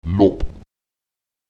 b [b] ähnelt dem b in Bach, wobei es von den Yorlaks allerdings „ploppend“ artikuliert wird.